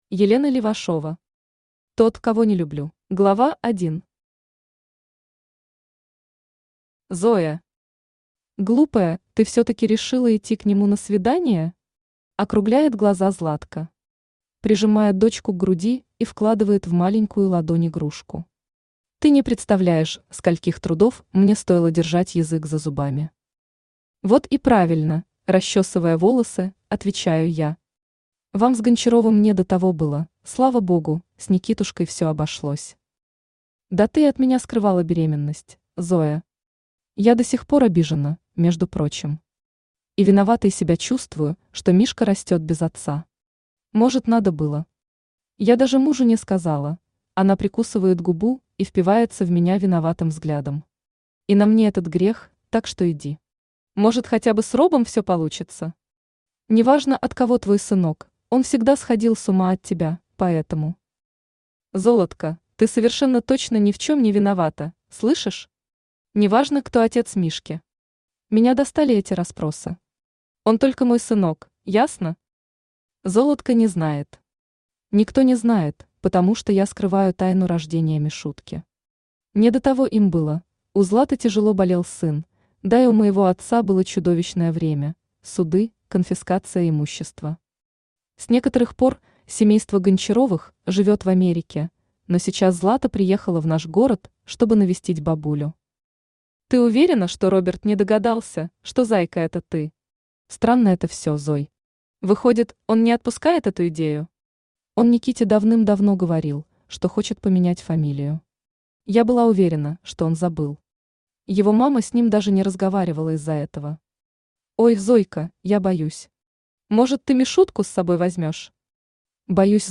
Аудиокнига Тот, кого не люблю | Библиотека аудиокниг
Aудиокнига Тот, кого не люблю Автор Елена Левашова Читает аудиокнигу Авточтец ЛитРес.